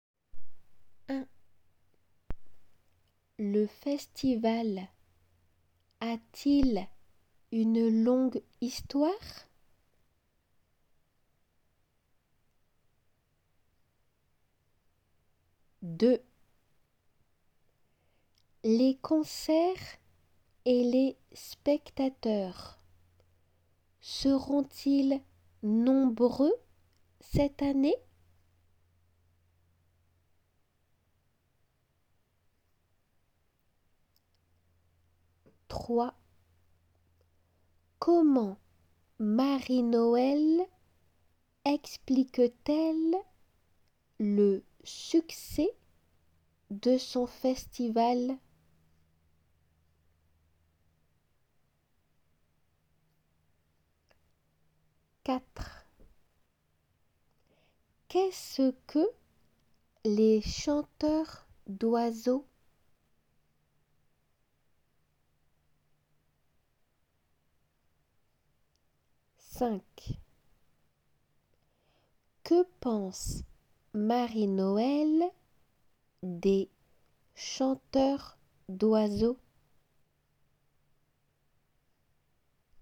読まれる質問